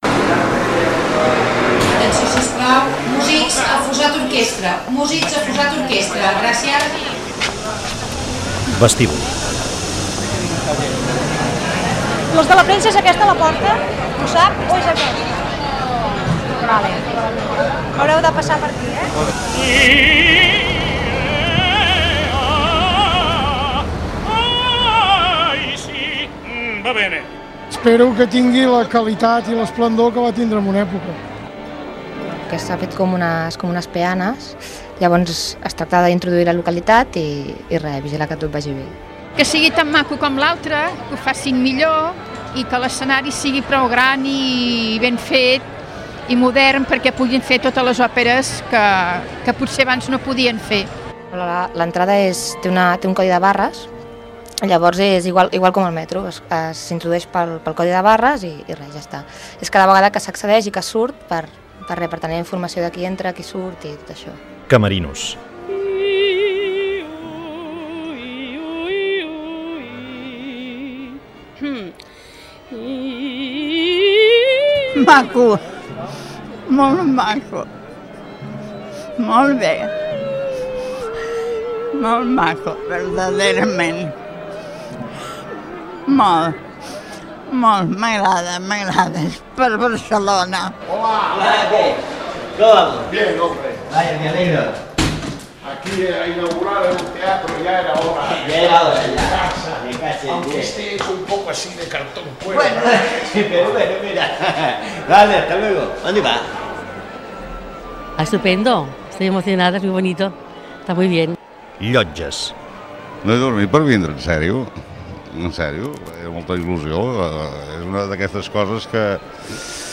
Recull de veus, opinions i so ambient preses en diferents llocs del teatre.
Programa únic produït per Broadcaster, amb motiu de la inauguració del nou Teatre del Liceu de Barcelona.